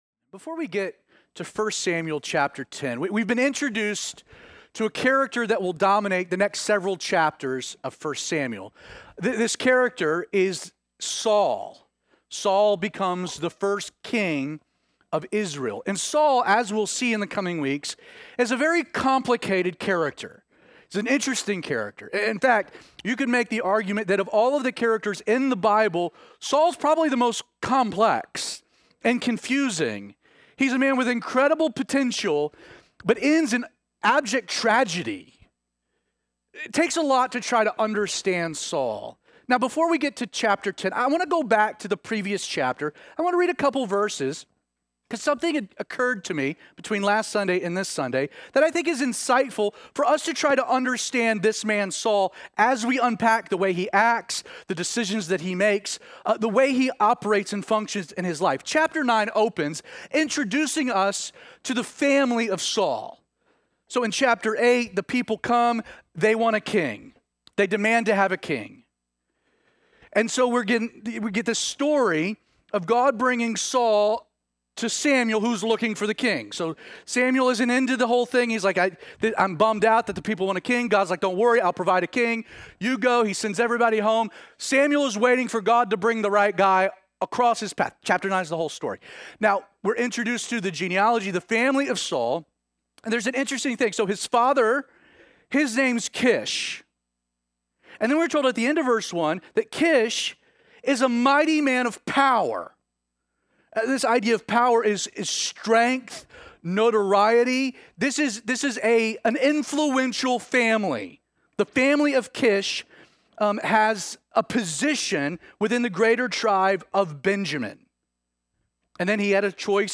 These message are recorded live at Calvary316 on Sunday mornings and posted later that afternoon.